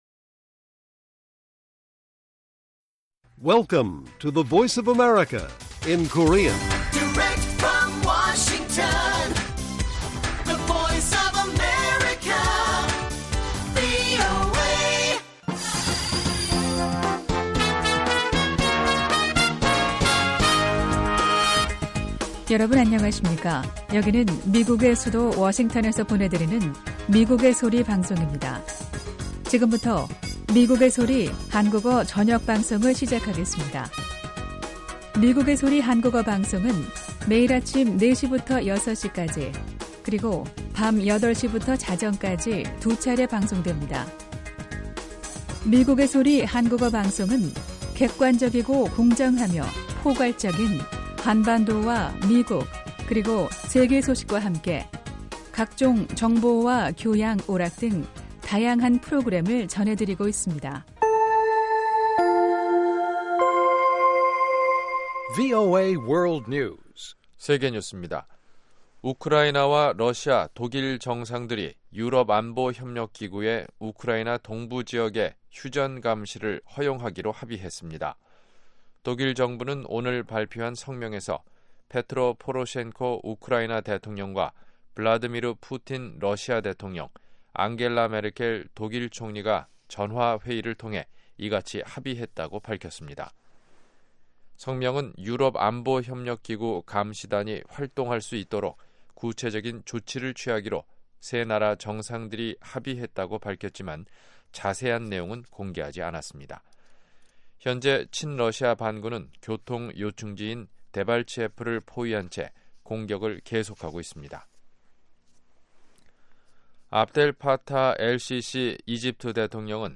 VOA 한국어 방송의 간판 뉴스 프로그램 '뉴스 투데이' 1부입니다. 한반도 시간 매일 오후 8시부터 9시까지 방송됩니다.